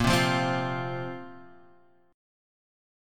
A#sus2 chord